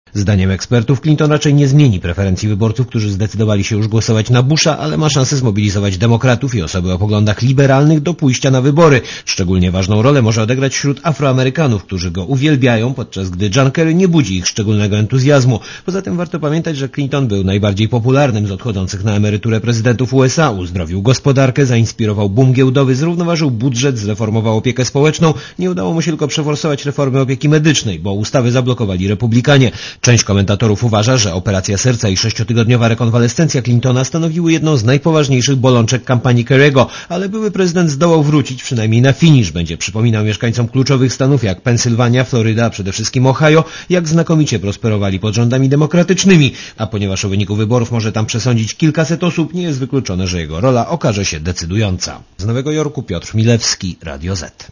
Korespondencja z USA A John Kerry próbuje zdobyć glosy gdzie się da, także wśród Amerykańskiej Polonii.